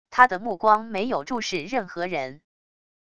他的目光没有注视任何人wav音频生成系统WAV Audio Player